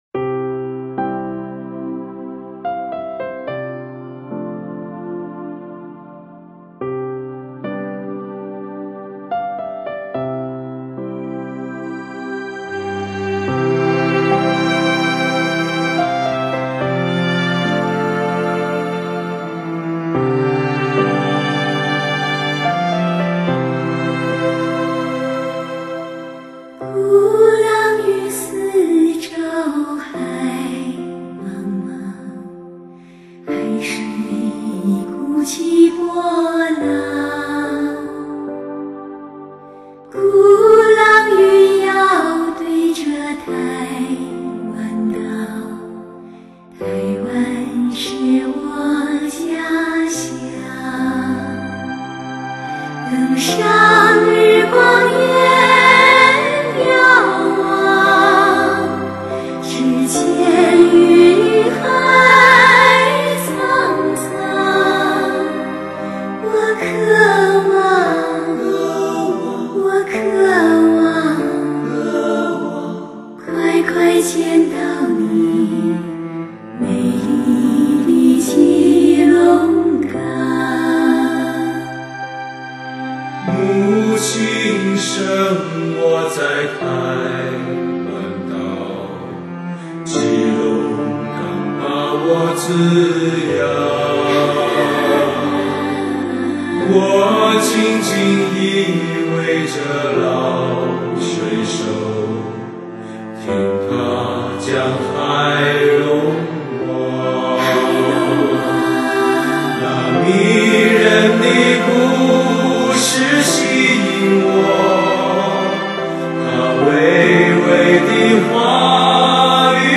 [mjh4][light]那歌声...那旋律...悠扬飘荡...[/light][/mjh4]